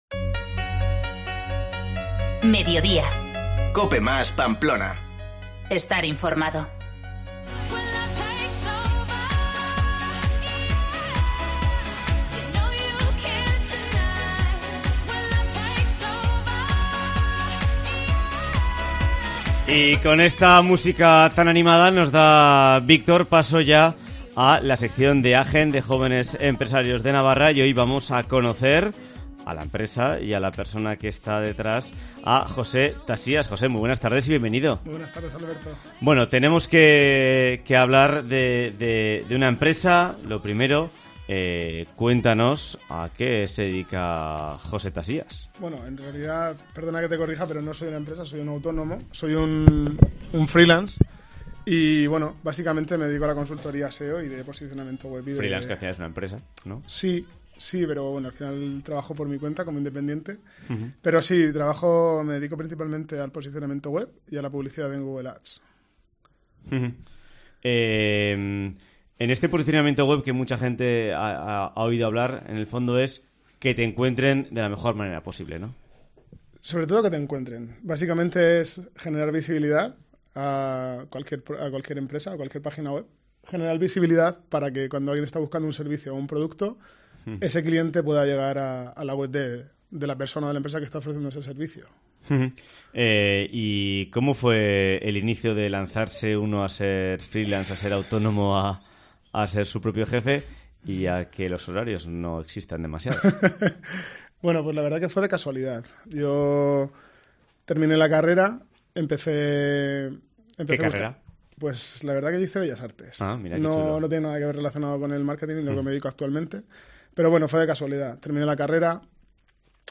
consultor SEO